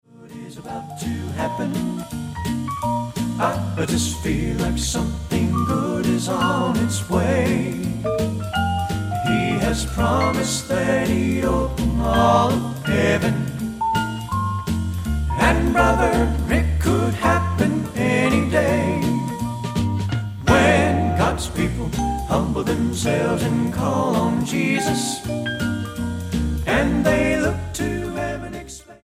STYLE: Southern Gospel
tender harmonies